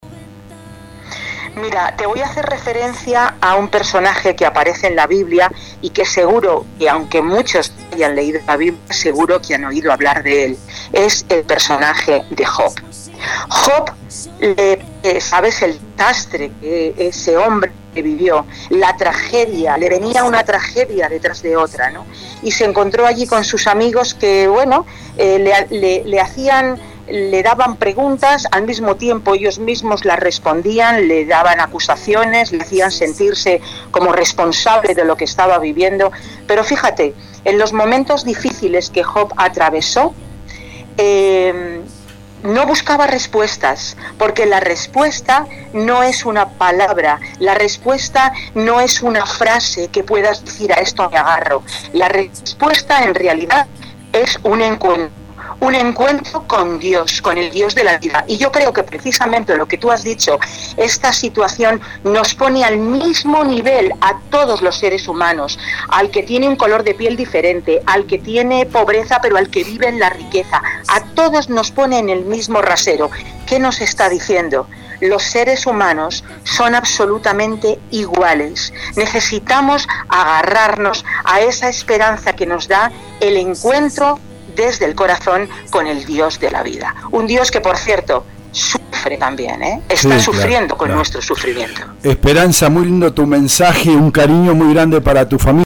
DESDE ESPAÑA: Hablamos con una locutora española sobre la crisis pandémica en su país “El ser humano es de corazón rebelde y no solidario” | EL DEBATE